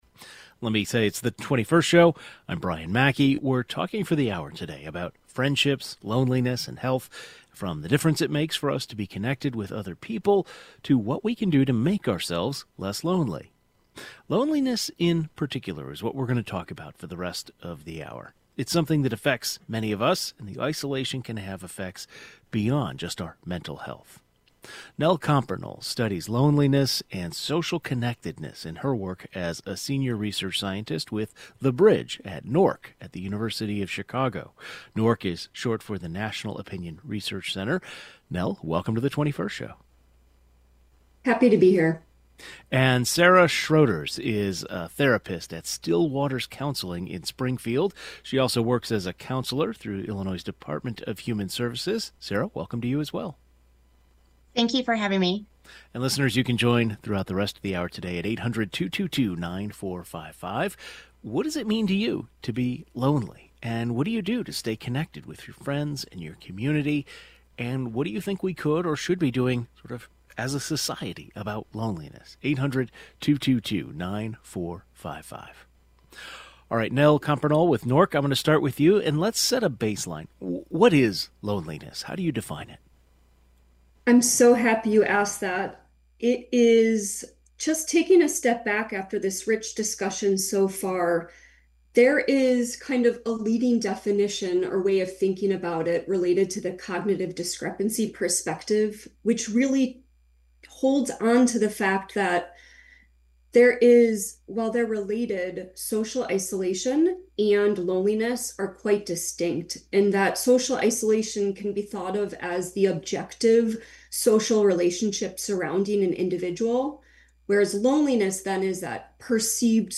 Is the U.S. facing an “epidemic of loneliness?" A researcher and a therapist discuss the effects of lonelines on mental health, which populations are more vulnerable, and what role relationships play in our lives.